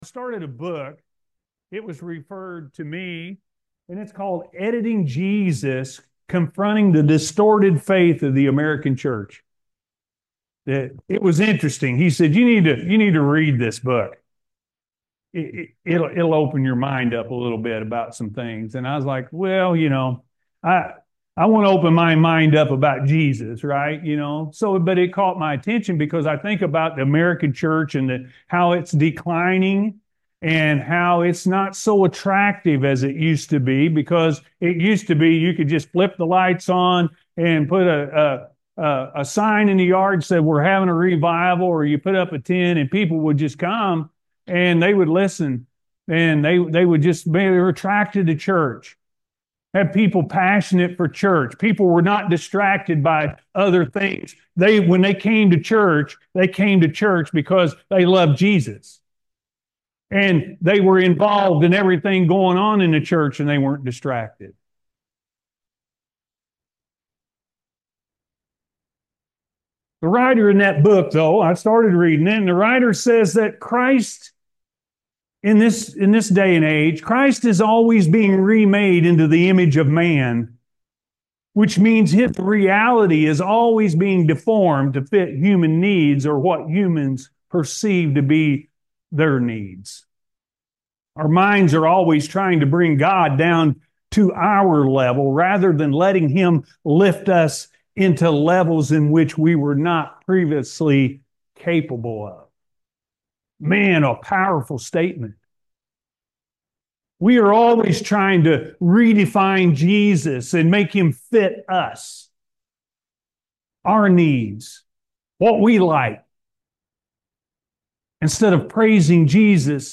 Jesus Promises The Holy Spirit-A.M. Service – Anna First Church of the Nazarene